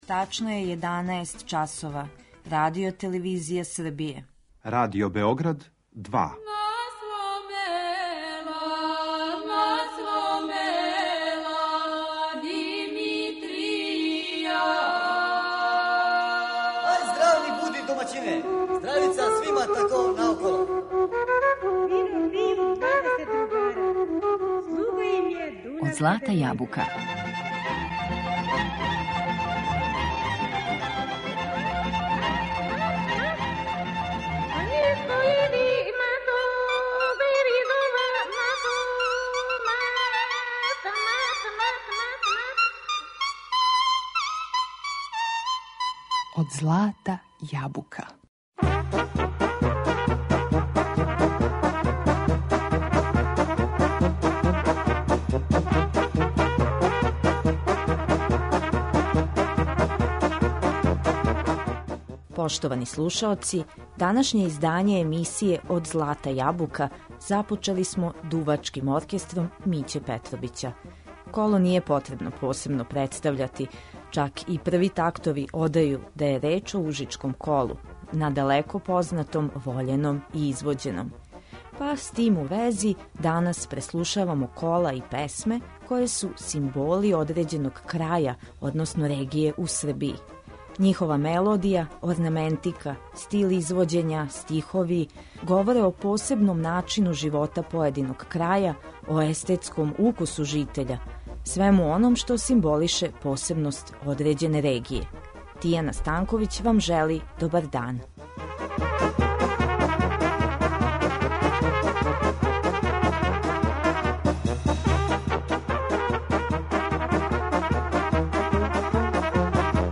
У данашњем издању емисије Од злата јабука преслушавамо кола и песме који су карактеристични за одређени крај Србије.